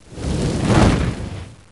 FIGHT-Fireball+1
Tags: combat